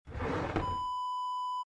Kitchen Drawer Open Wav Sound Effect #5
Description: The sound of a kitchen drawer pulled open
Properties: 48.000 kHz 16-bit Stereo
A beep sound is embedded in the audio preview file but it is not present in the high resolution downloadable wav file.
Keywords: kitchen, slide, sliding, roll, out, rollout, drawer, shelf, push, pull, open
drawer-kitchen-open-preview-5.mp3